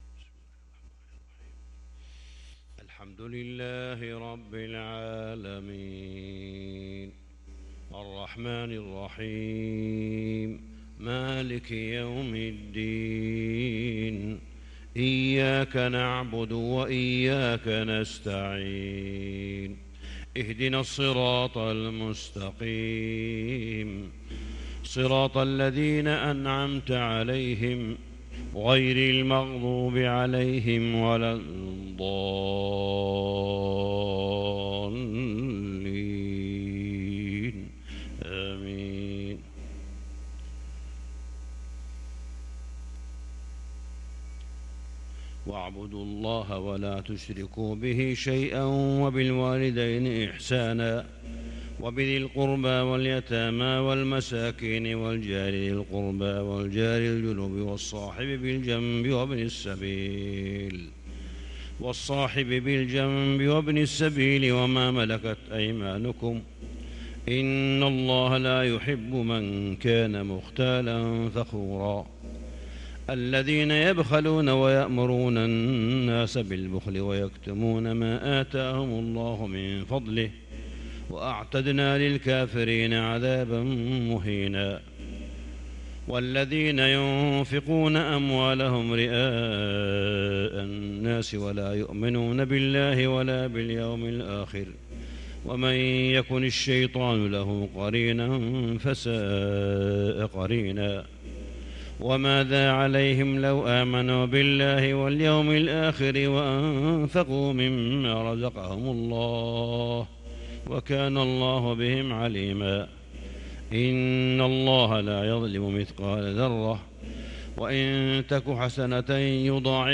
صلاة الفجر للقارئ صالح بن حميد 24 صفر 1444 هـ
تِلَاوَات الْحَرَمَيْن .